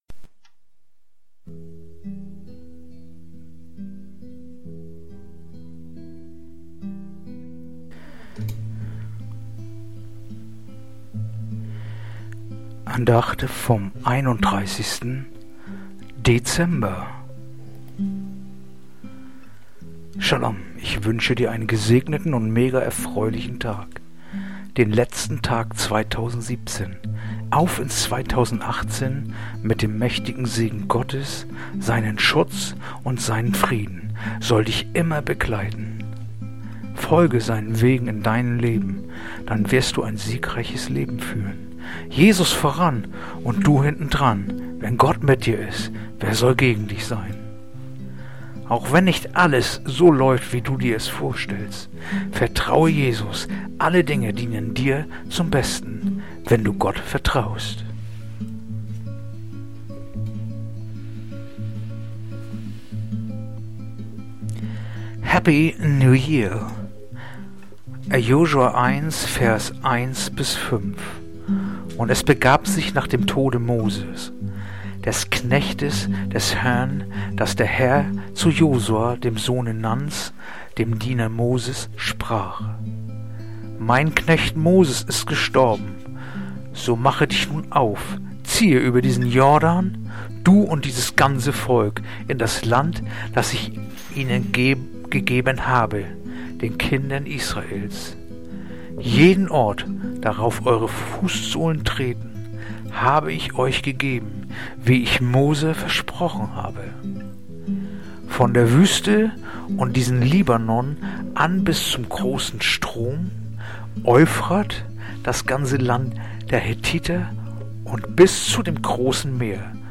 Andacht-vom-31-Dezember-Josua-1-1-5.mp3